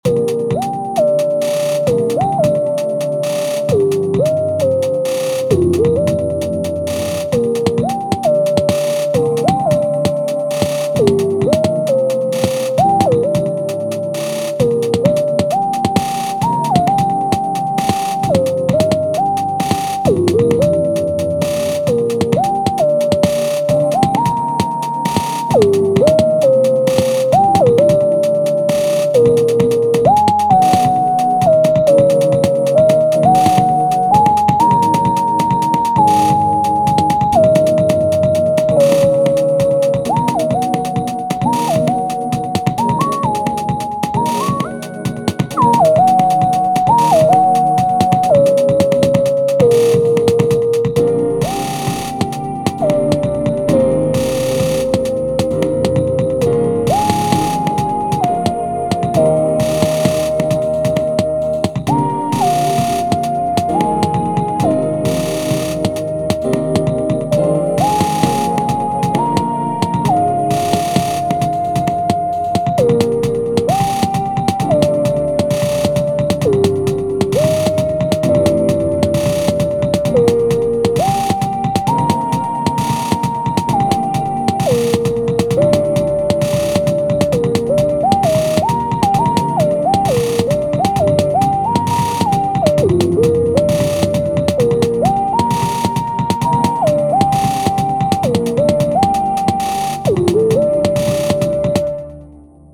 Attempt at switching time signatures mid-song. accidentally learned that 6/4 is my favorite time signature.